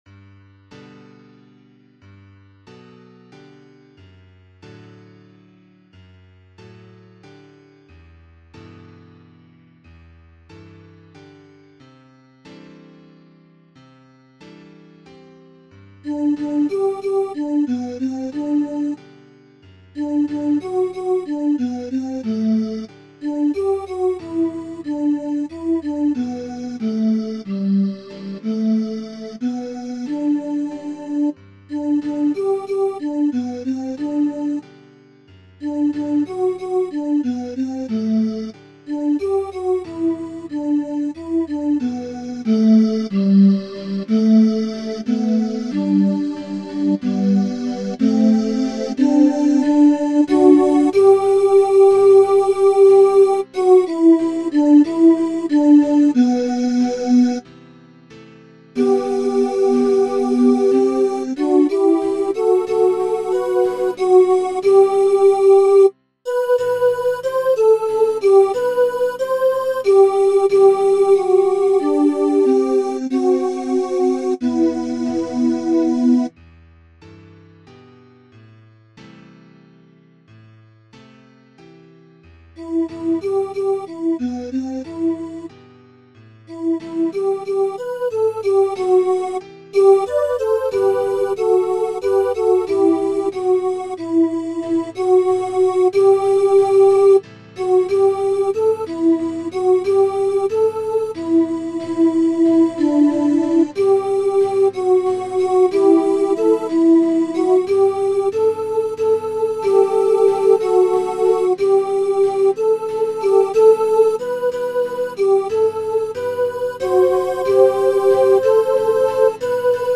Voicing/Instrumentation: SA , Duet